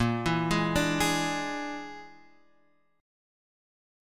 A#7b5 Chord